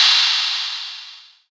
OPEN HAT 3.wav